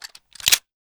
glock20_sliderelease.wav